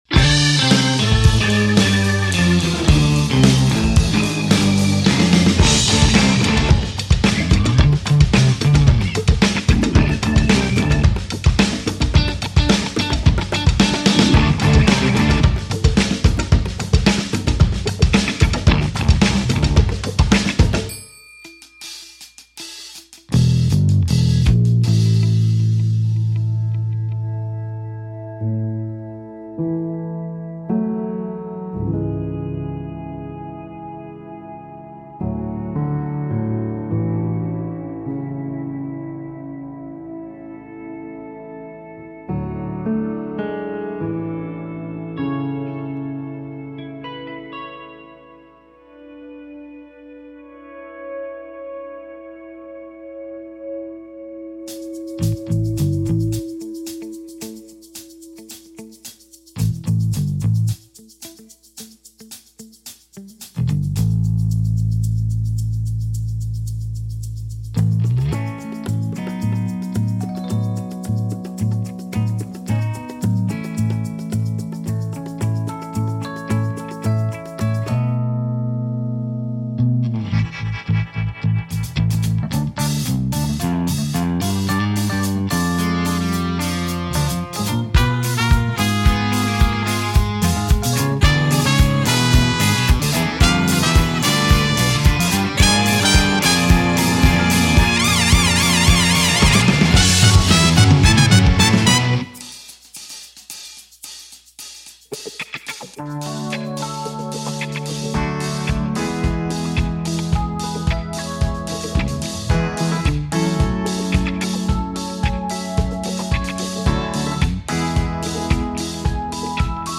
De la pop-rock agréable, à la fois soignée et rigolote.